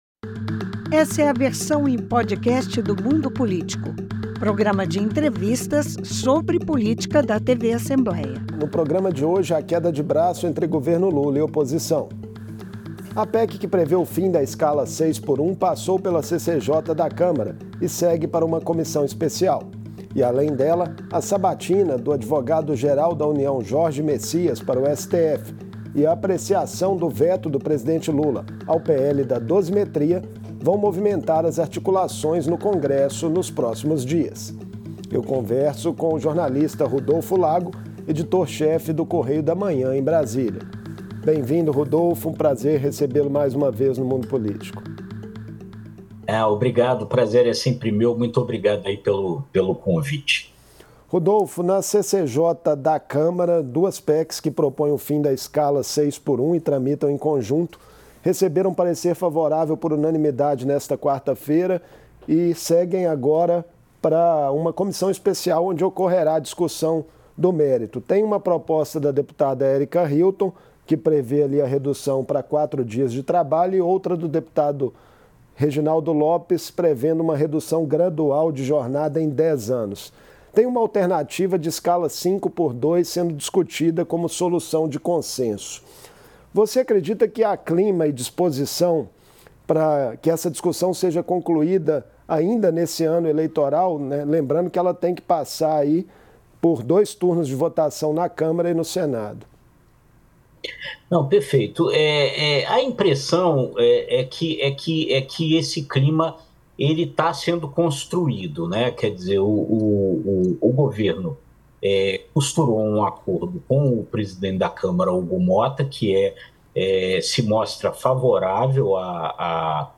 Os próximos dias serão movimentados por pautas populares no Congresso. Em entrevista